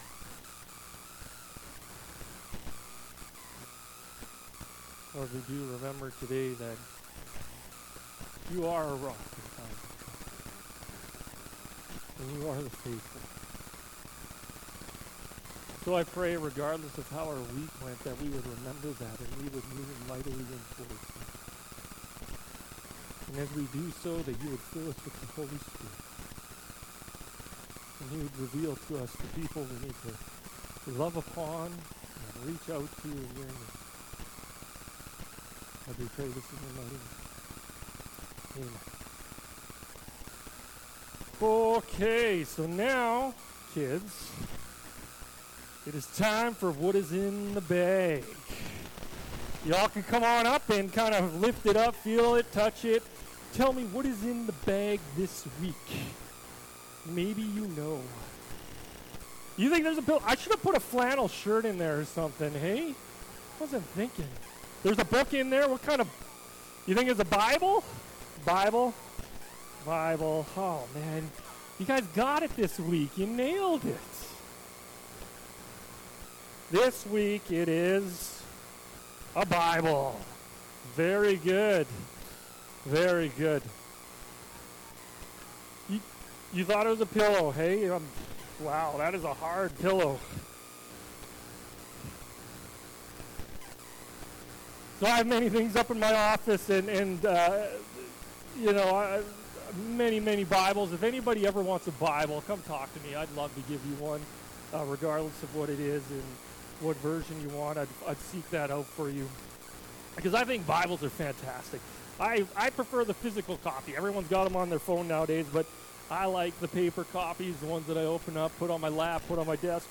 Sermons | Muir Lake Community Alliance Church